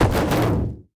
vehicle_strike.ogg